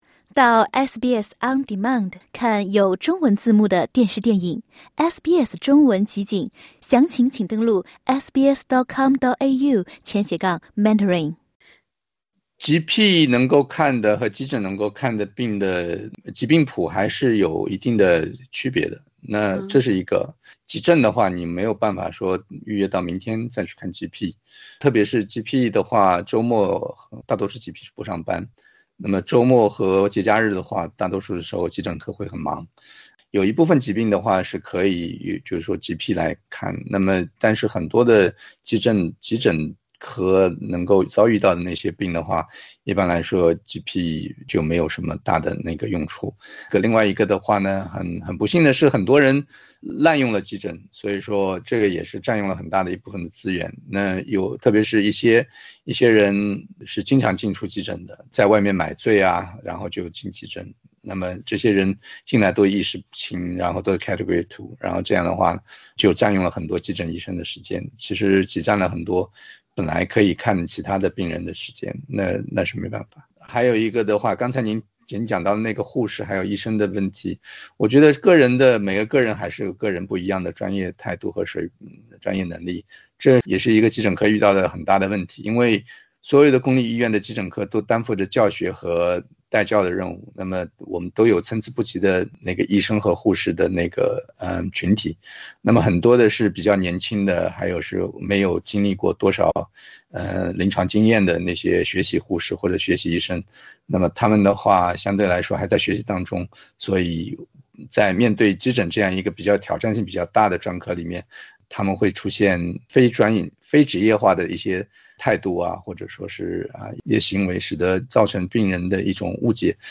参与讨论的澳洲急诊医生介绍说，周末GP休息、急诊病谱广，加上有人滥用急诊资源，澳洲公立医院急诊室人满为患，急诊医生还得边看病边带教，常常很难知道自己能几点下班。